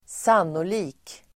Uttal: [²s'an:oli:k]